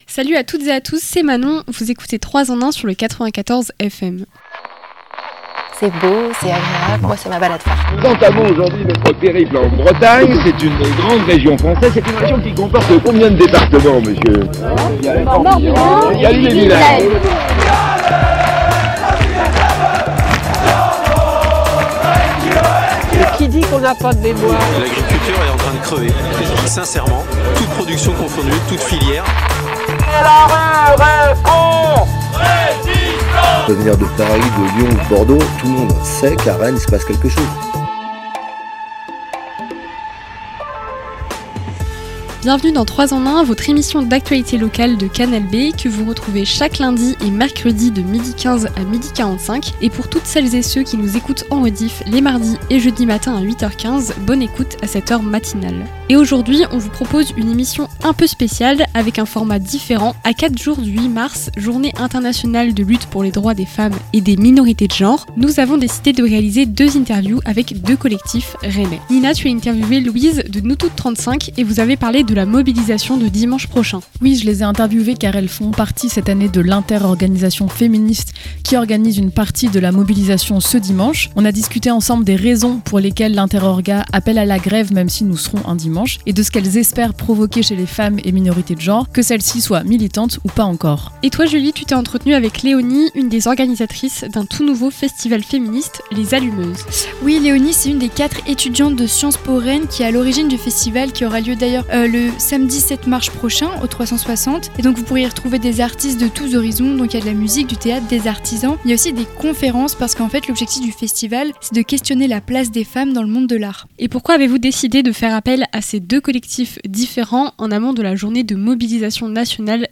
4 jours avant le 8 mars, Journée internationale de lutte pour les droits des femmes et des minorités de genre, l'équipe de 3 EN 1 vous propose une émission spéciale. Au programme, deux interviews avec deux collectifs : NousToutes35 et Les Allumeuses !